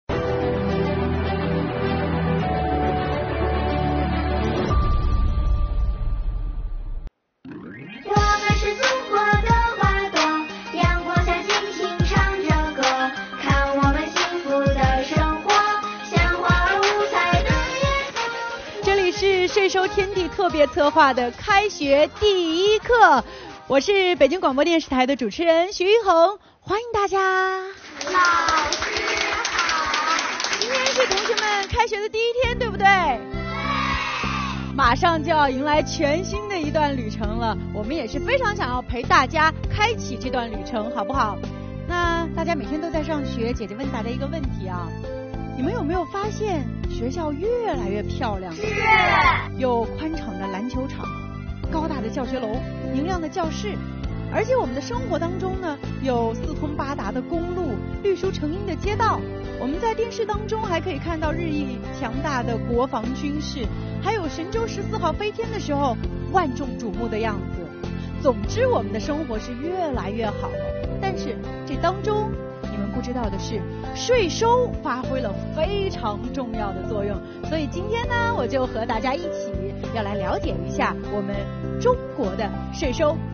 今天是开学第一天，让我们一起来听一堂有趣的税法普及课，了解税收是什么，税款都去哪了，我们又可以为税收做些什么？